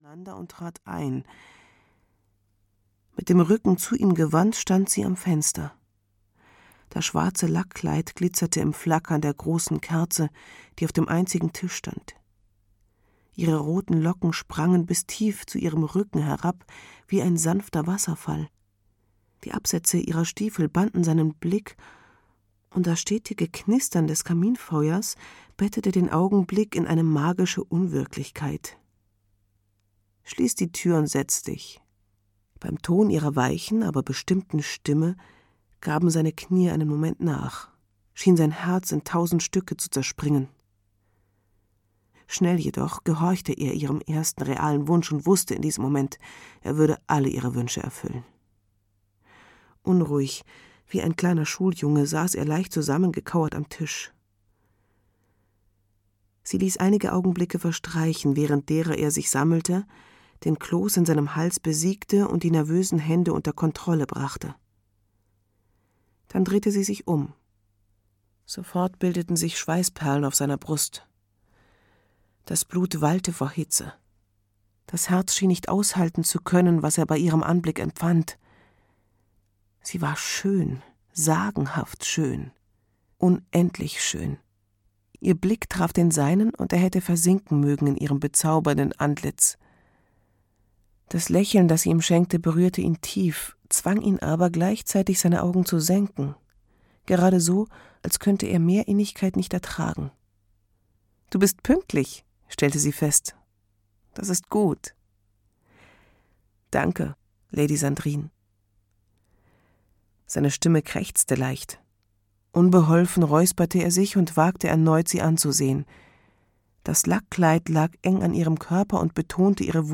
Im Dienste der Lady - Lady Sandrine - Hörbuch